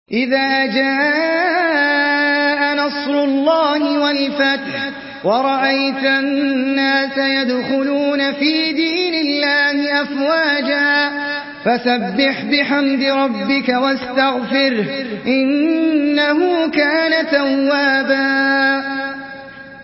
Surah আন-নাসর MP3 by Ahmed Al Ajmi in Hafs An Asim narration.
Murattal Hafs An Asim